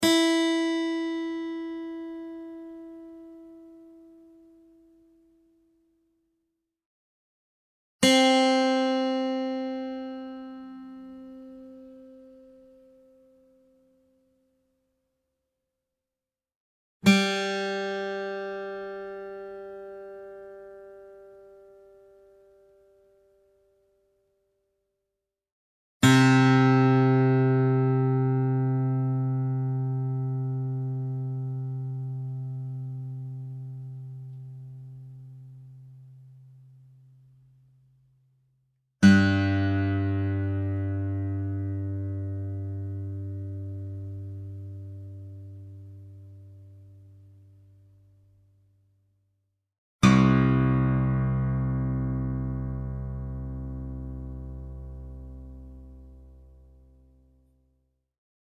Real acoustic guitar sounds in Open C Tuning
Guitar Tuning Sounds